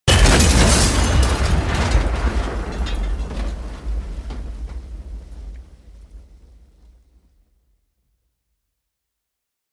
دانلود آهنگ ماشین 22 از افکت صوتی حمل و نقل
دانلود صدای ماشین 22 از ساعد نیوز با لینک مستقیم و کیفیت بالا
جلوه های صوتی